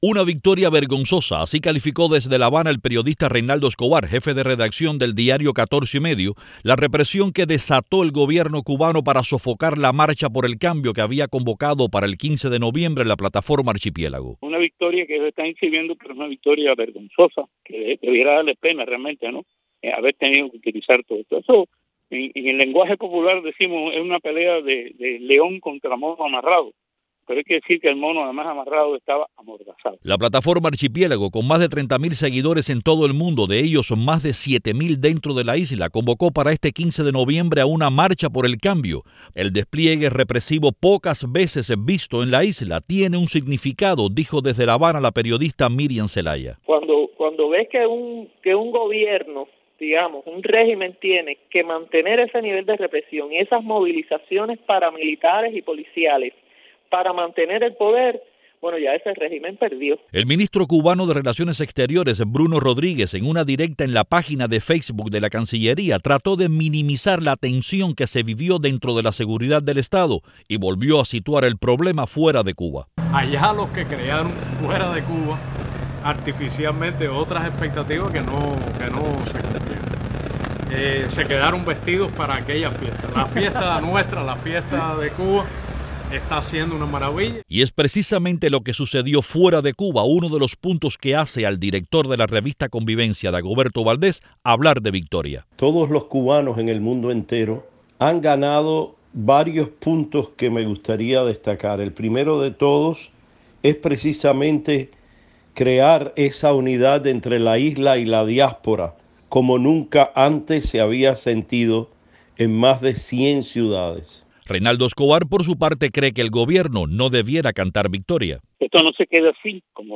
Así opinaron desde la isla analistas consultados por